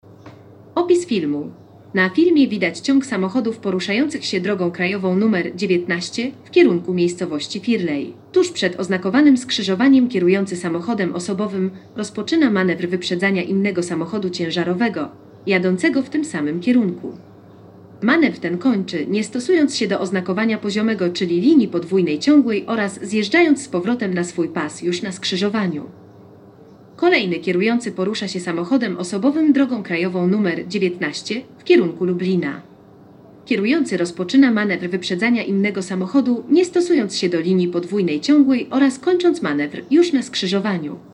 Nagranie audio Audiodeskrypcja_filmu_policyjne_dzialania_z_uzyciem_drona.mp3